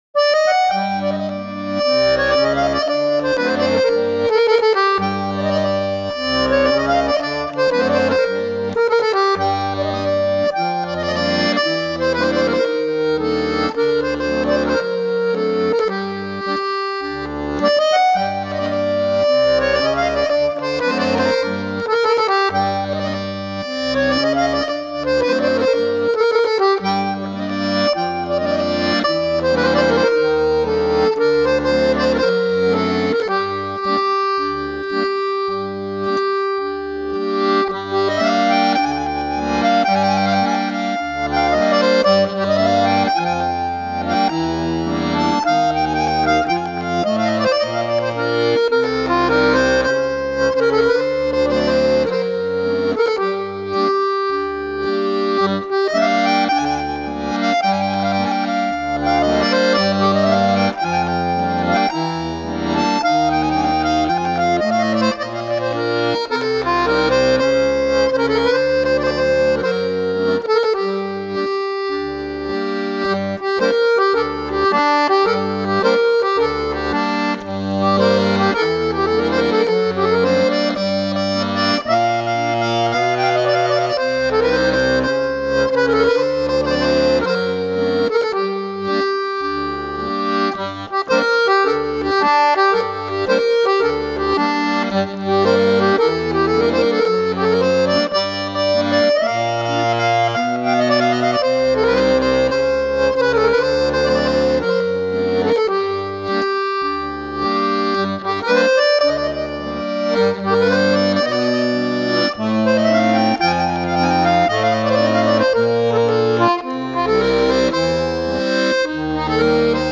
petite chanson Roumaine